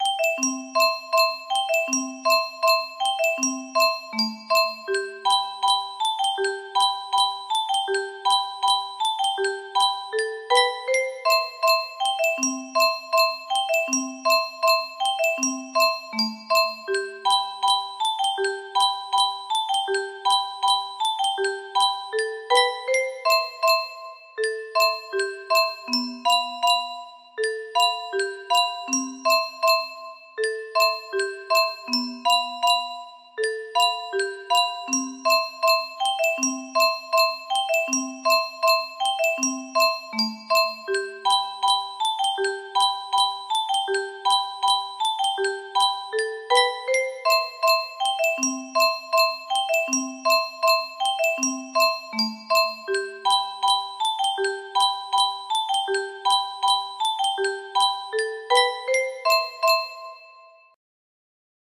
Собачий вальс music box melody